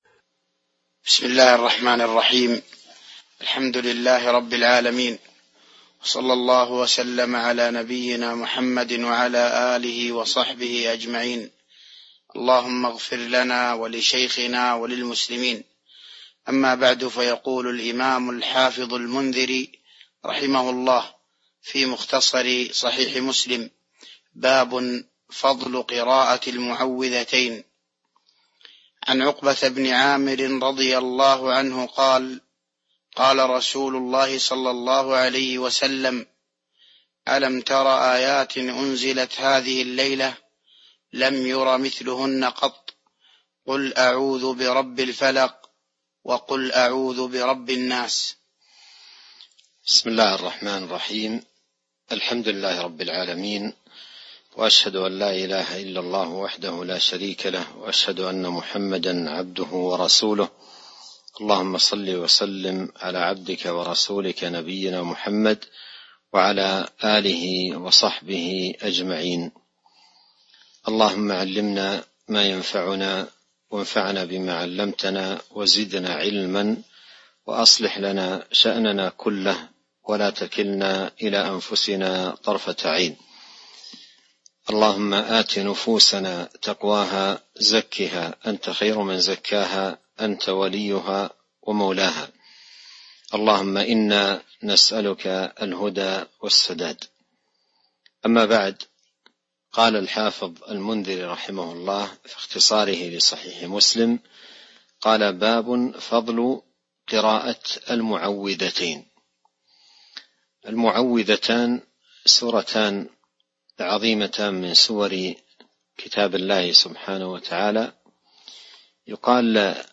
تاريخ النشر ٧ رمضان ١٤٤٢ هـ المكان: المسجد النبوي الشيخ